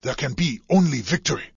角色语音